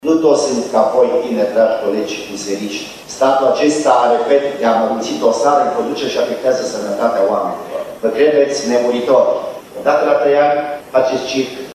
05aug-11-Boc-in-sedinta.mp3